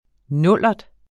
Udtale [ ˈnɔlˀʌd ]